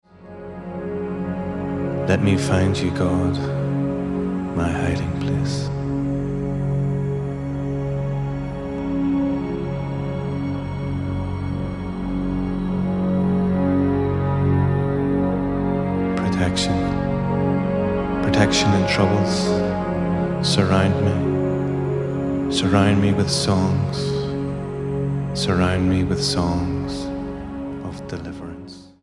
Ambient/Meditational
Techno
Style: Dance/Electronic